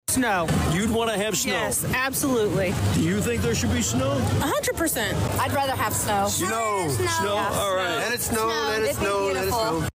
AUDIO: (Downtown Danville Parade of Lights attendees calling for snow.)